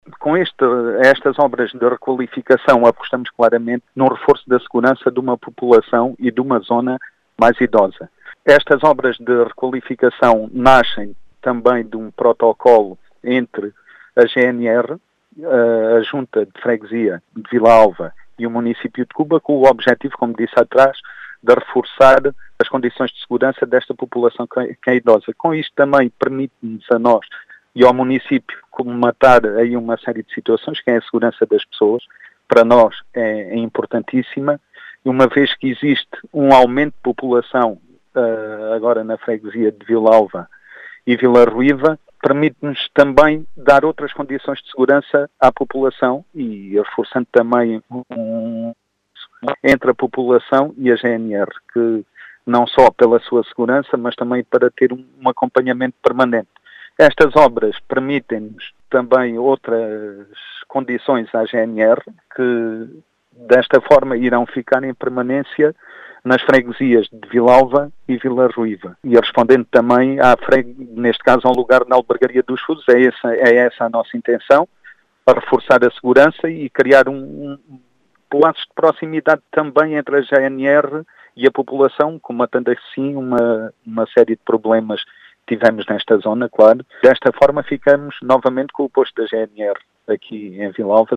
As explicações foram deixadas pelo vice-presidente da Câmara Municipal de Cuba, Filipe Chora, que realça a importância do “reforço da segurança”, numa altura em que “existe um aumento de população em Vila Alva e Vila Ruiva”, dando outras condições também aos militares da GNR.